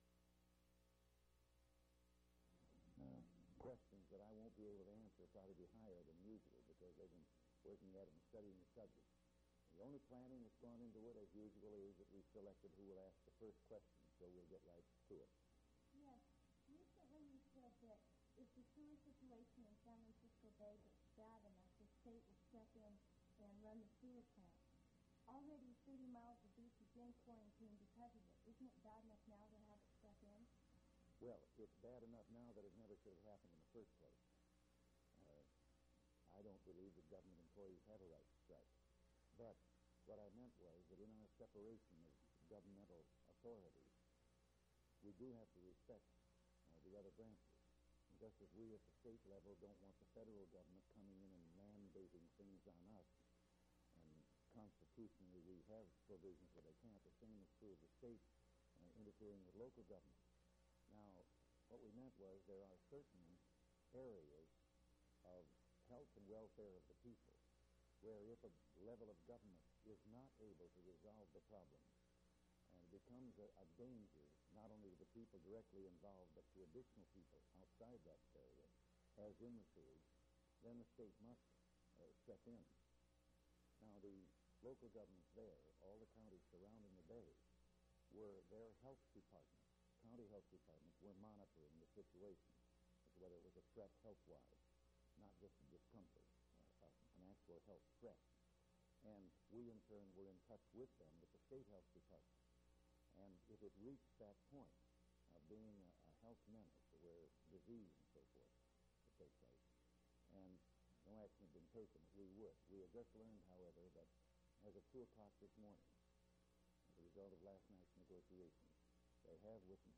Governor Ronald Reagan Question and Answer with students
Audio Cassette Format.